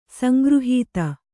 ♪ sangřhīta